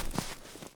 update snow step sounds
snow_3.ogg